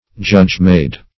Judge-made \Judge"-made`\, a.